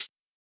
hat.wav